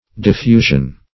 Diffusion \Dif*fu"sion\, n. [L. diffusio: cf. F. diffusion.]